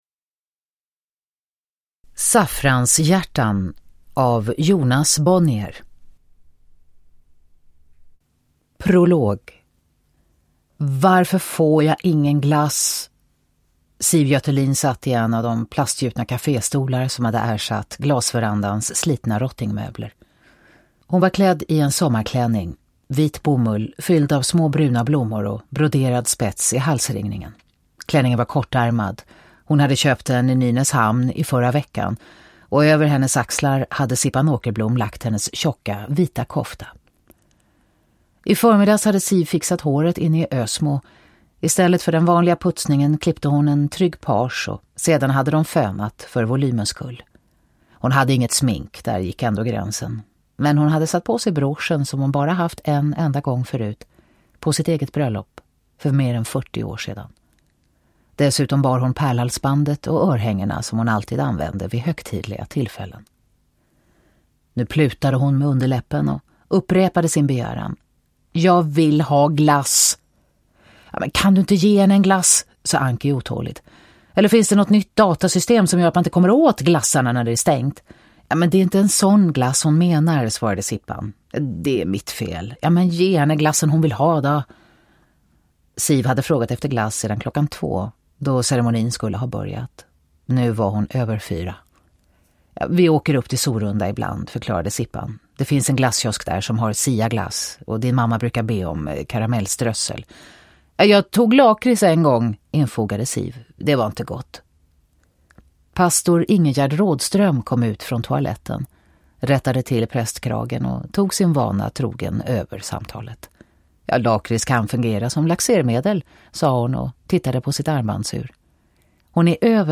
Uppläsare: Katarina Ewerlöf
Ljudbok
Och Katarina Ewerlöfs uppläsning ger en perfekt inramning!